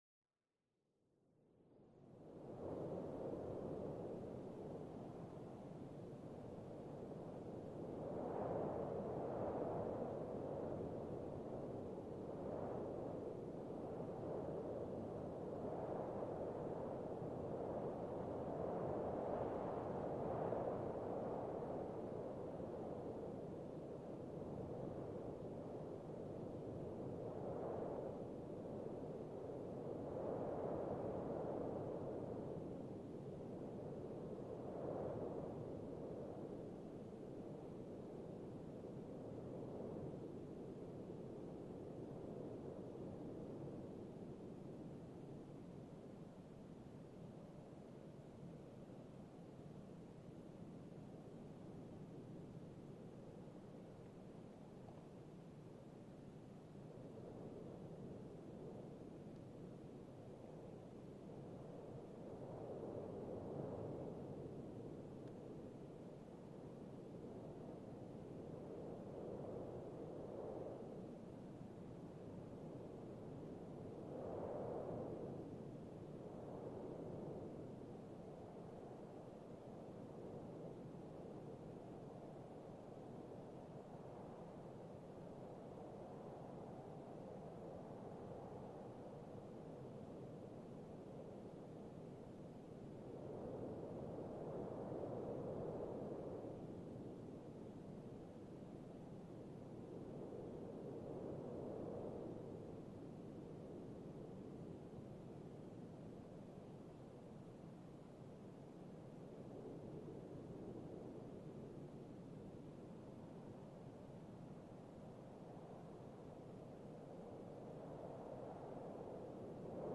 D’autres fois, c’était pour rendre compte d’un site particulier où le vent nous parle du paysage et de la dure vie des hommes, c’était le cas, de nuit comme de jour dans le Désert de Gobie.
LE VENT DANS LE DÉSERT DE GOBIE